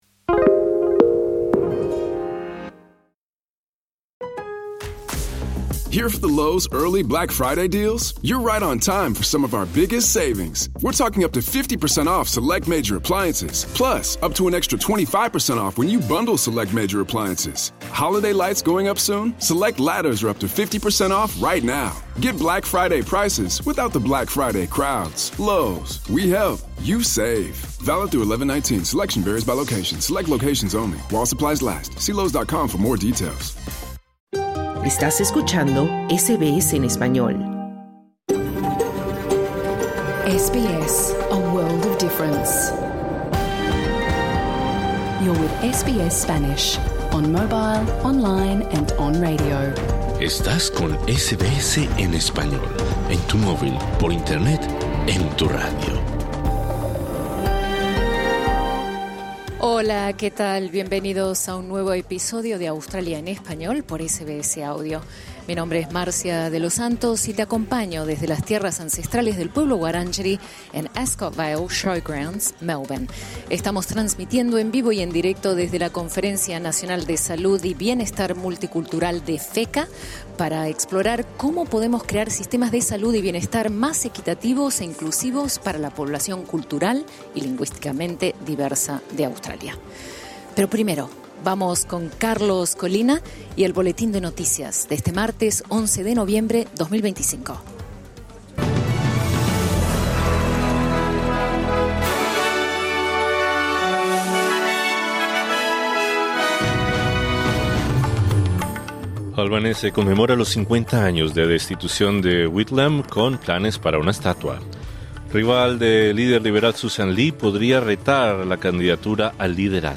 Conversamos con varias líderes expertas en temas de salud, inclusión social, cuidados en la vejez y salud mental en Australia, quienes son la voz de la comunidad hispana en la Conferencia Nacional de Salud y Bienestar Multicultural 2025, organizada por FECCA, en Melbourne.
TEMAS DEL PROGRAMA: Te presentamos un programa especial desde la Conferencia Nacional de Salud y Bienestar Multicultural 2025, realizada en Melbourne y organizada por la Federation of Ethnic Communities’ Councils of Australia. Líderes de diversas comunidades se reunieron para debatir sobre la inclusividad en el sistema de salud australiano y de los desafíos que enfrentan las comunidades multiculturales en Australia en temas relacionados con salud y bienestar.